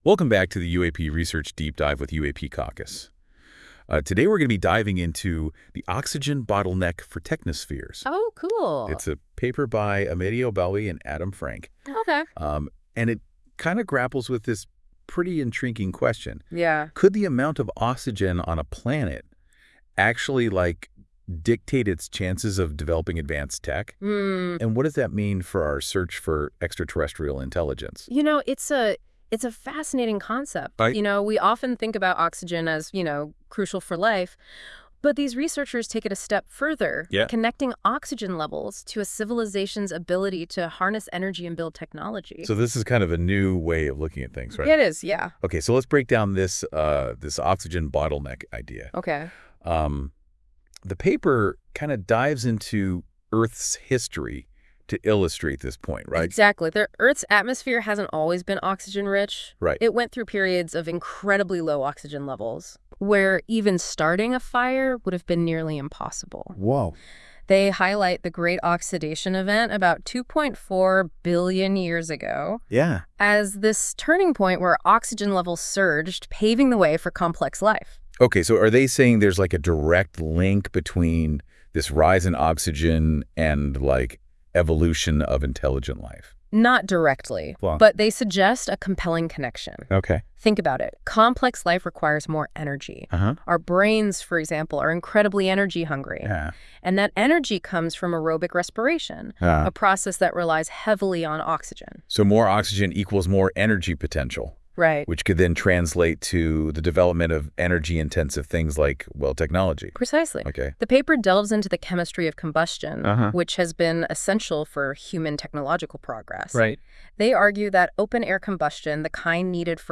Powered by NotebookLM. This AI-generated audio may not fully capture the research's complexity.
Audio Summary